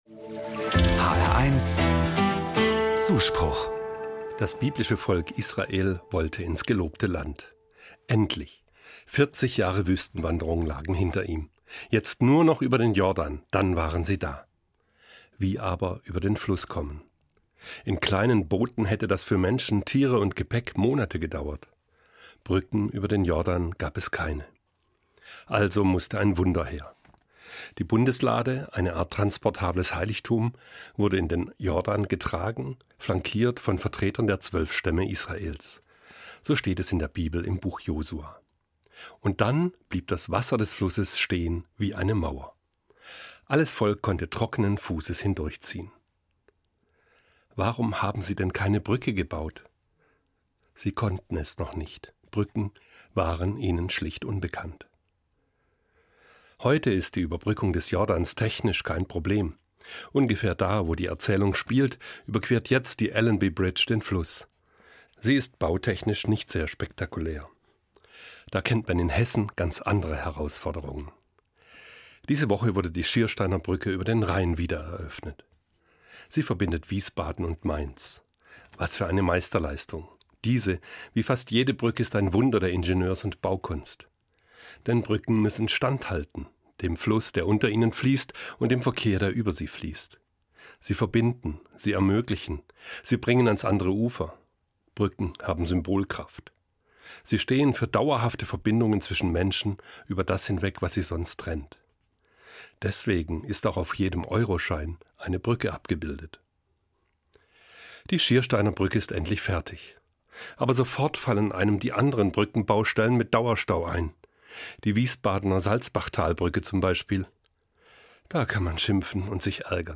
Evangelischer Pfarrer, Frankfurt